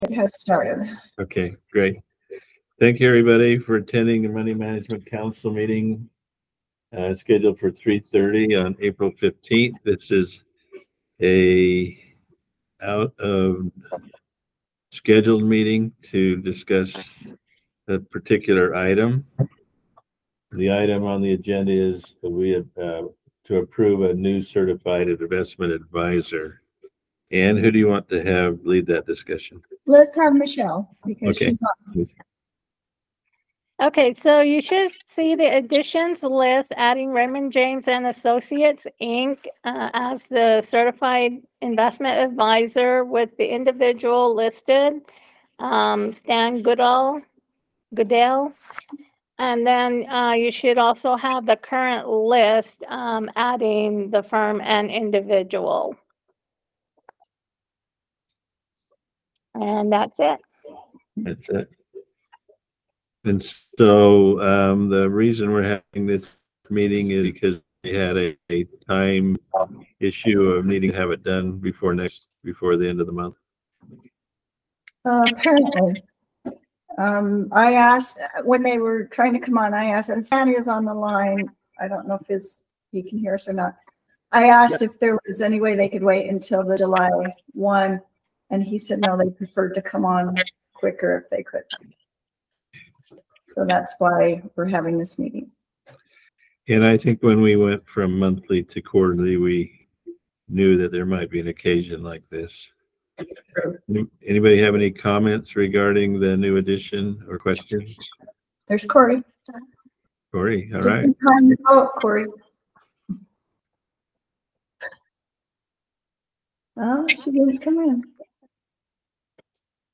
Meeting
One or more Members of the Authority may participate via telephonic conference originated by the Chair, and the meeting shall be an electronic meeting, and the anchor location shall be the offices of the State Treasurer set forth above, within the meaning